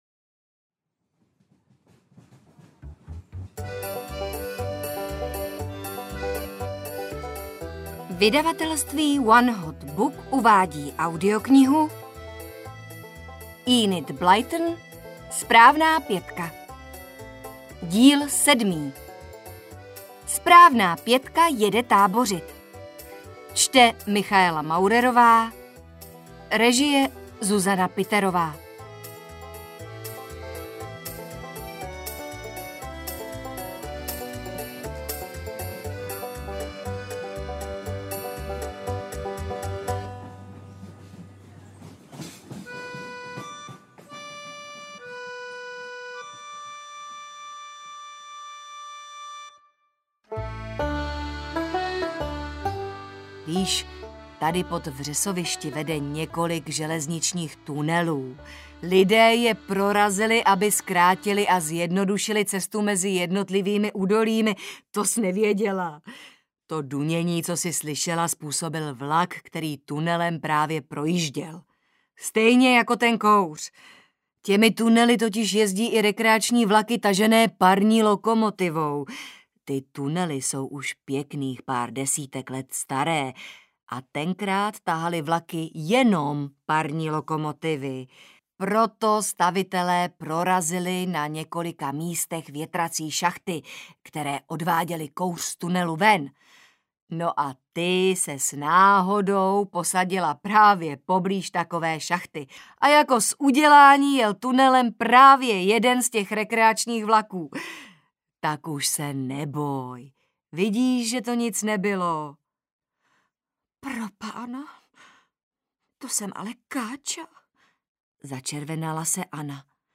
SPRÁVNÁ PĚTKA jede tábořit audiokniha
Ukázka z knihy